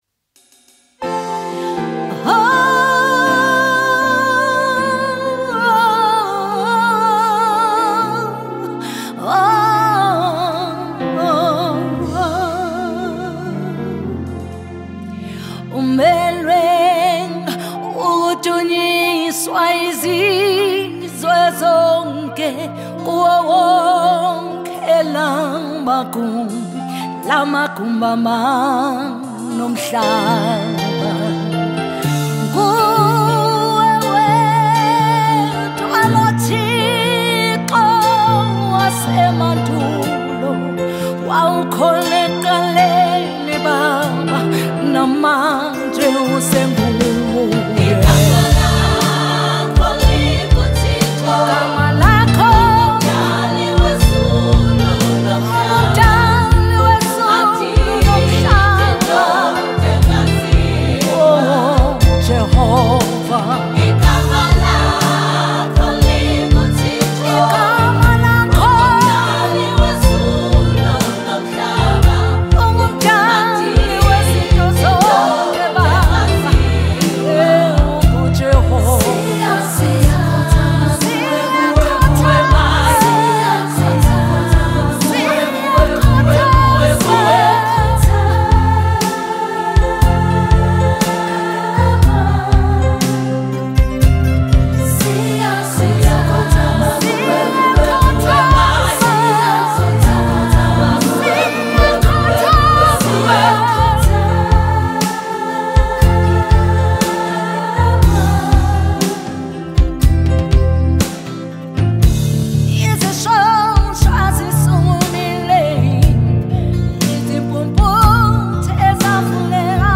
February 4, 2025 Publisher 01 Gospel 0